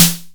snare 2.wav